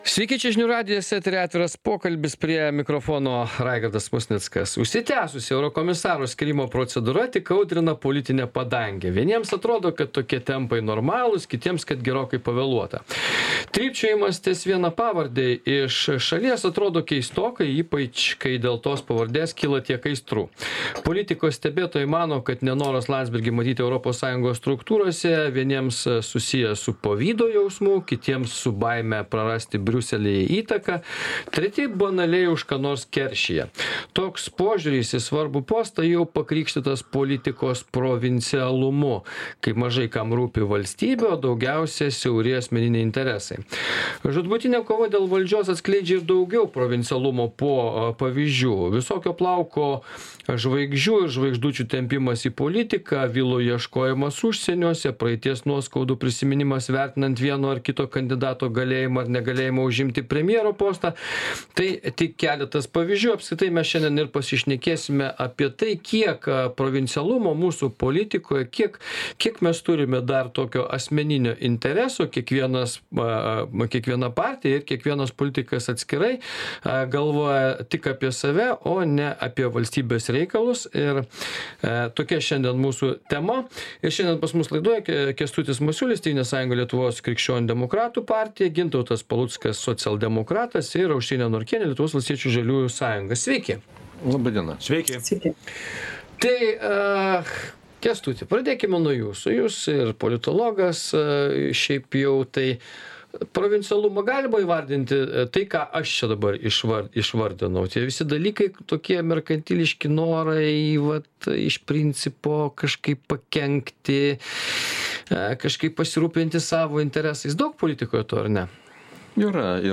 Laidoje dalyvauja konservatorius Kęstutis Masiulis, socialdemokratas Gintautas Paluckas ir valstietė Aušrinė Norkienė.